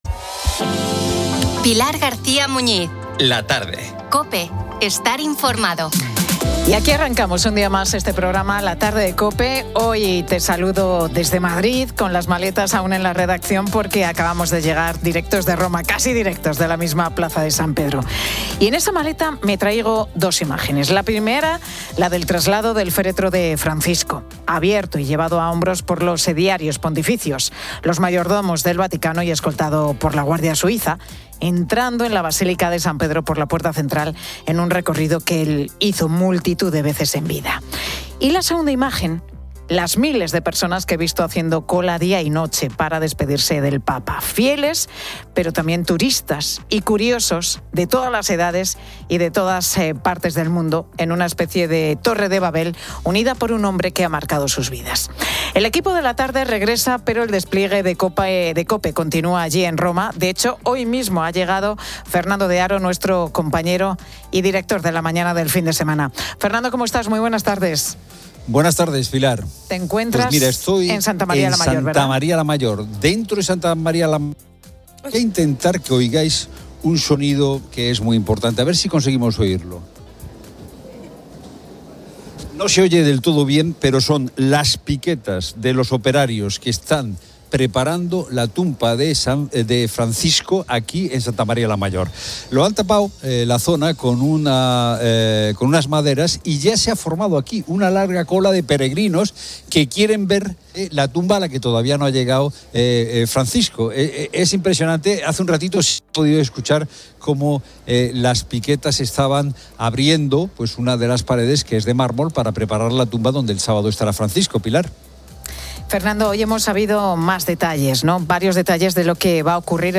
Hoy te saludo desde Madrid con las...